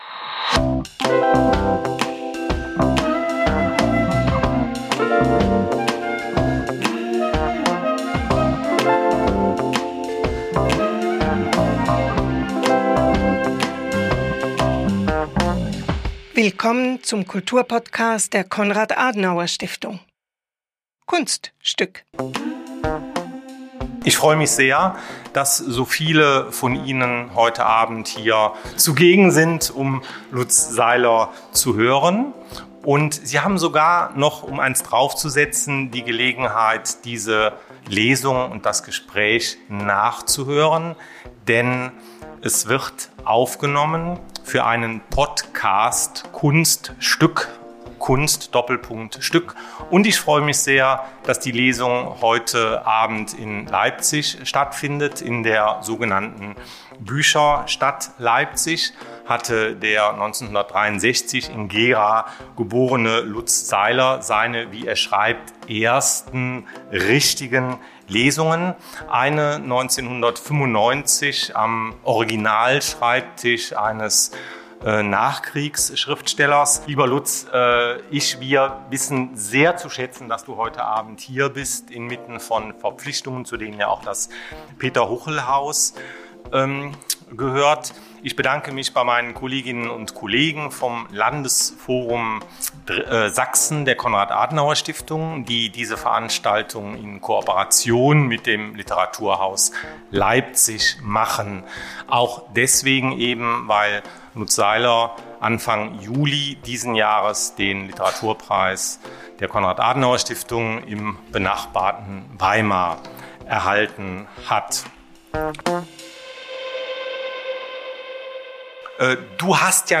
Lutz Seiler erzählt Geschichten vom schwierigen Weg zur Freiheit. Bei einer Lesung im Literaturhaus Leipzig gibt der KAS-Literaturpreisträger Einblicke in seine Schreibwerkstatt. Hören Sie den Mitschnitt der Veranstaltung des KAS-Bildungsforum Sachsen.